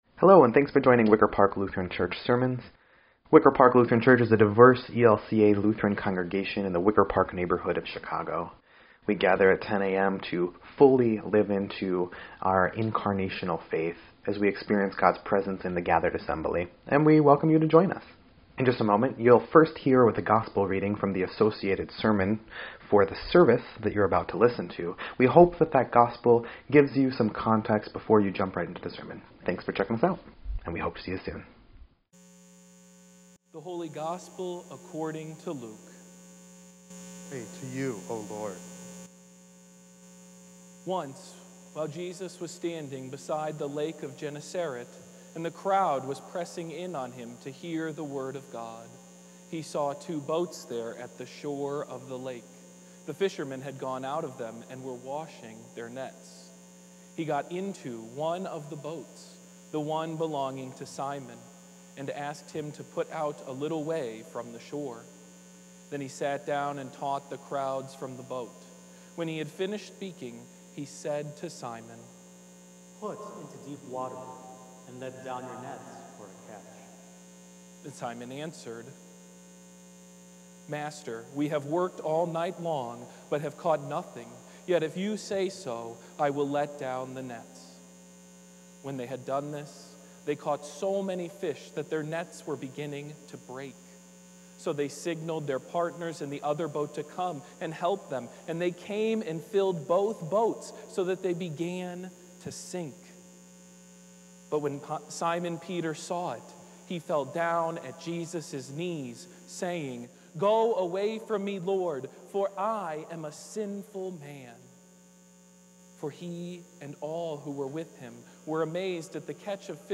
2.6.22-Sermon_EDIT.mp3